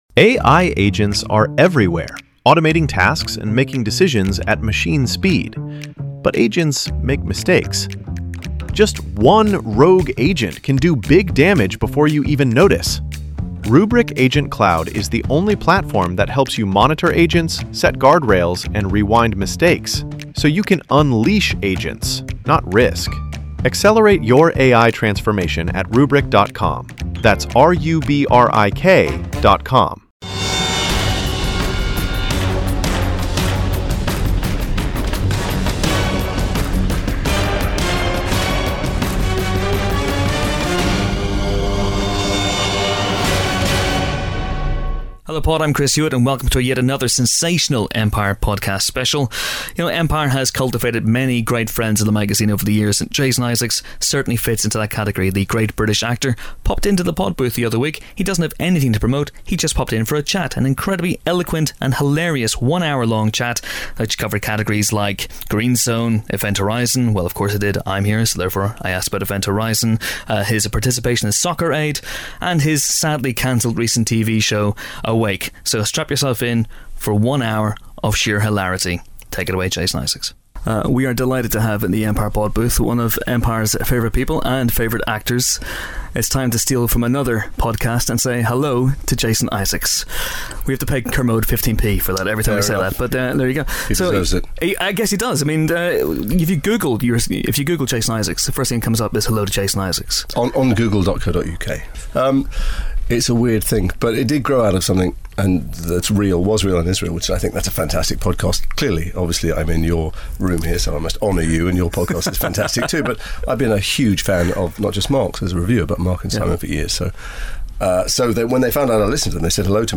Jason Isaacs Special The Empire Film Podcast Bauer Media Tv & Film, Film Reviews 4.6 • 2.7K Ratings 🗓 20 July 2012 ⏱ 63 minutes 🔗 Recording | iTunes | RSS 🧾 Download transcript Summary The one and only Jason Isaacs stepped into our humble podcast recording studio recently, and here is the incredibly eloquent and really rather funny result, touching on everything from Soccer Aid to Event Horizon, Harry Potter to fake moustaches.